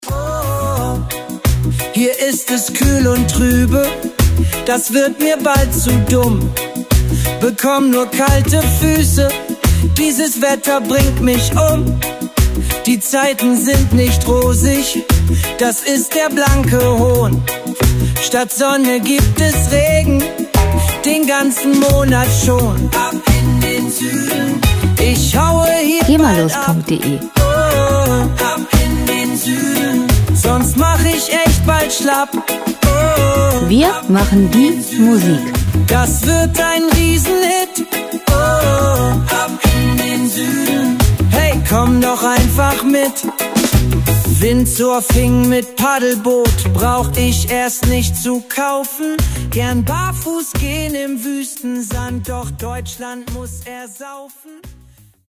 Pop Musik aus der Rubrik: "Popwelt Deutsch"
Musikstil: Reggae
Tempo: 88 bpm
Tonart: H-Dur
Charakter: amüsant, locker
Instrumentierung: Sänger, E-Gitarre, E-Bass, Drums, Piano